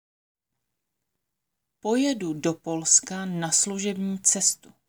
Tady si můžete stáhnout audio na výslovnost akcentu: Pojedu do Polska na služební cestu.